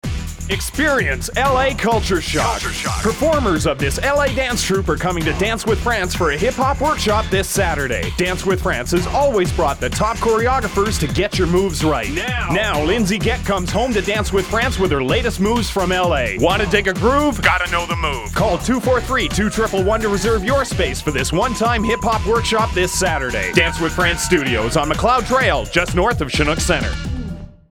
Dance With France Commercial
All of the above audition examples were written and/or Voiced here.